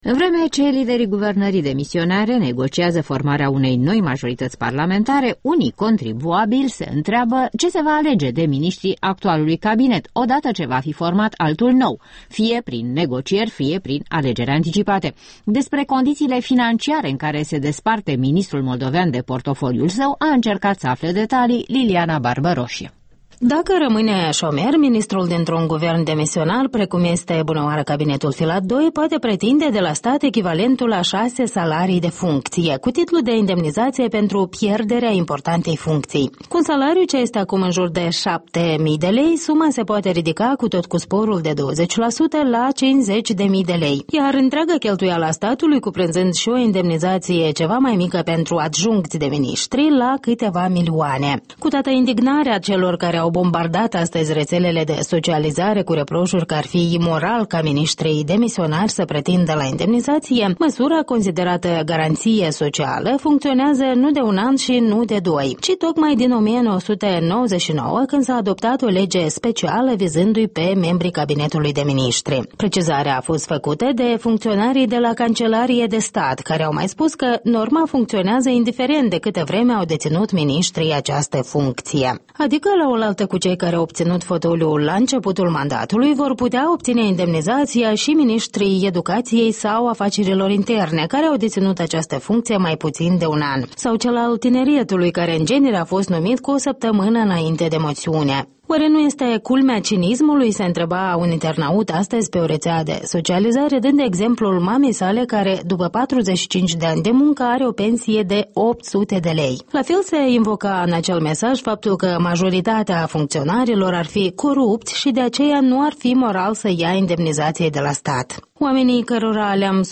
Un reportaj despre indemnizațiile la care au dreptul... miniștrii la ieșirea din funcție